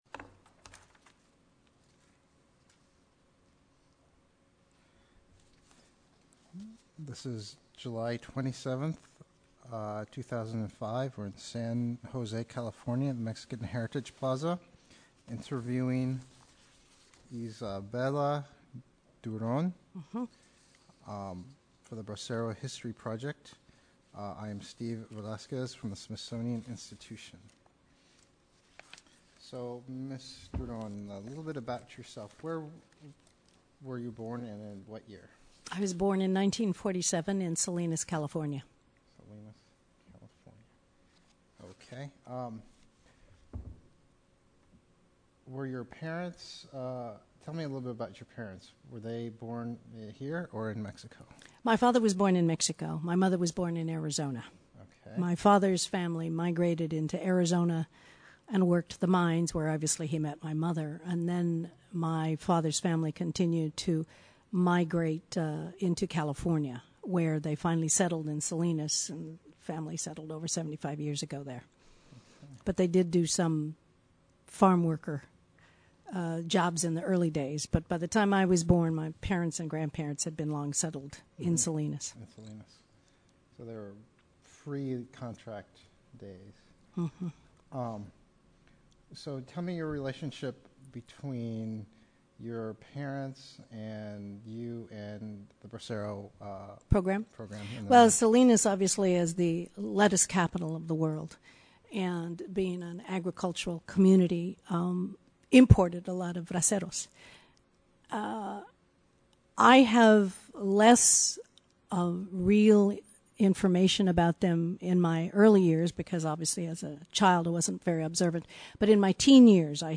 Original Format Mini disc
Location San Jose, CA